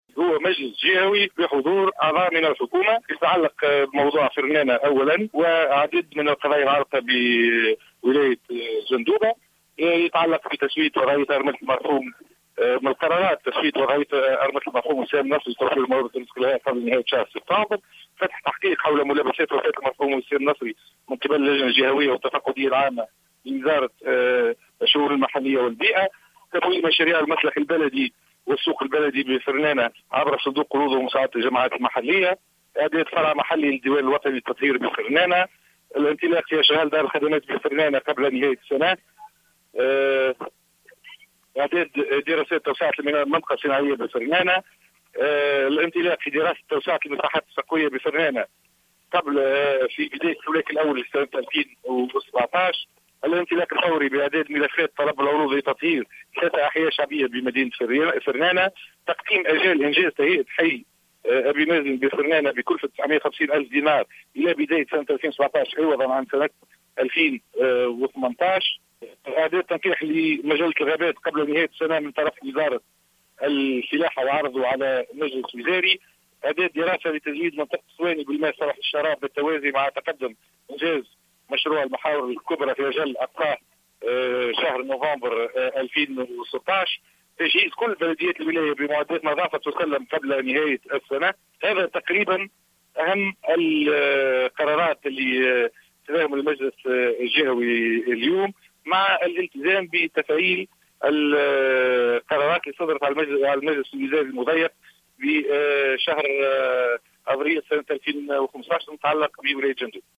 اكد الوزير الناطق باسم الحكومة اياد الدهماني في تصريح للجوهرة" اف ان" على اثر حضوره بالمجلس الجهوي بولاية جندوبة بحضور وزير الفلاحة والموارد المائية والصيد البحري سمير بالطيب ووزير الشؤون المحلية و البيئة رياض الموخر أنه تم اتخاذ جملة من الإجراءات لفائدة المنطقة خلال هذه الزيارة